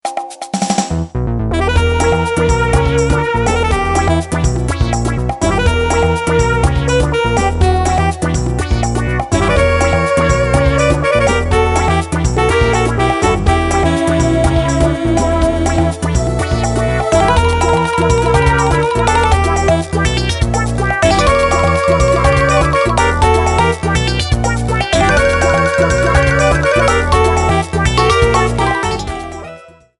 minigame music rearranged